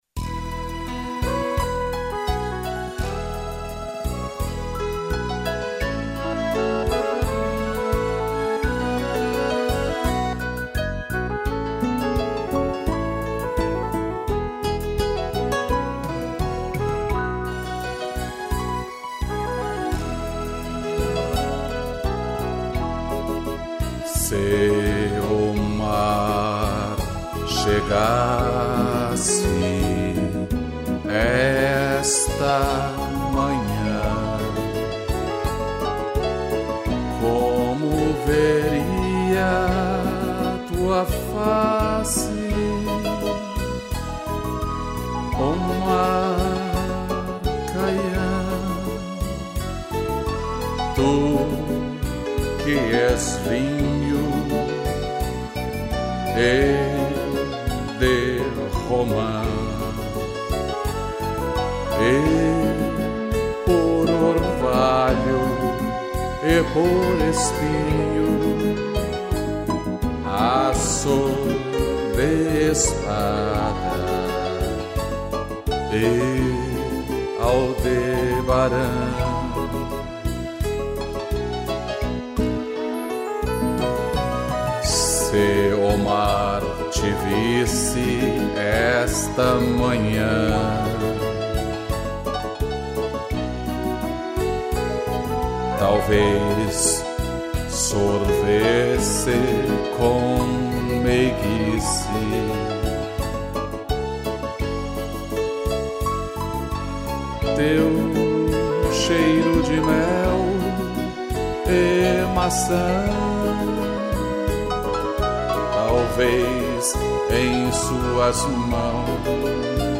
Flugel Horn e piano